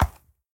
horse_soft5.ogg